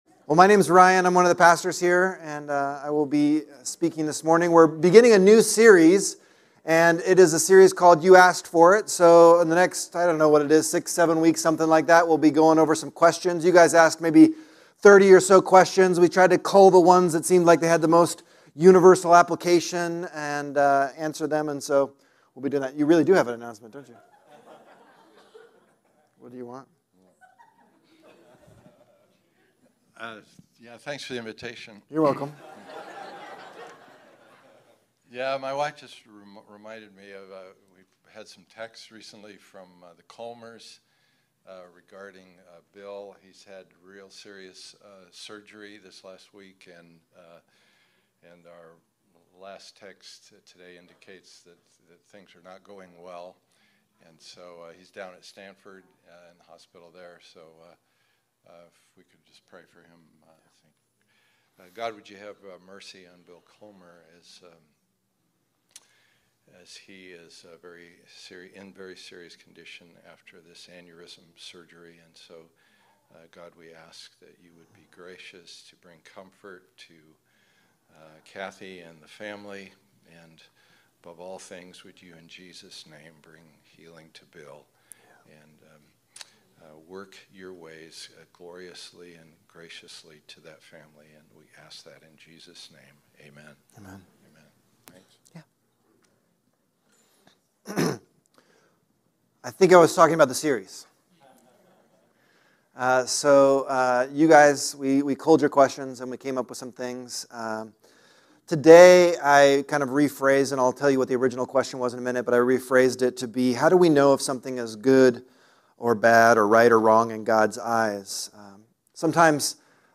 Type: Sermons Book